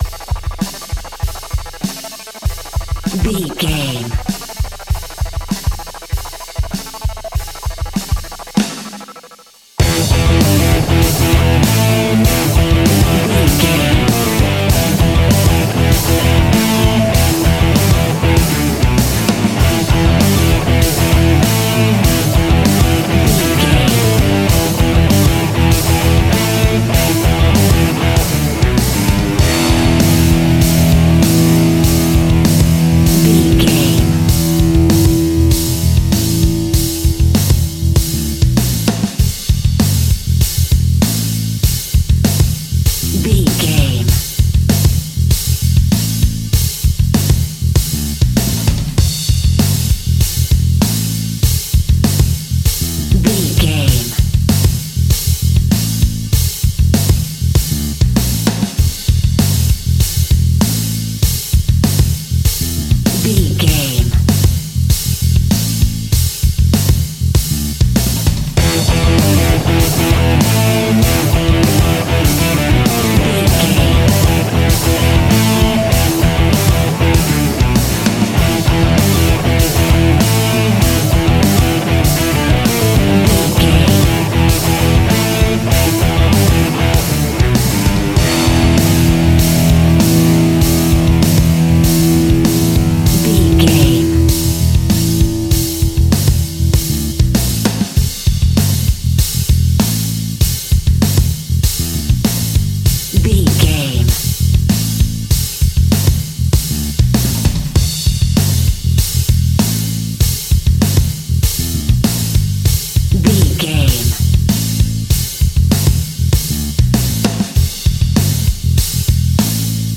Ionian/Major
energetic
driving
heavy
aggressive
electric guitar
bass guitar
drums
hard rock
heavy metal
blues rock
distortion
instrumentals
heavy drums
distorted guitars
hammond organ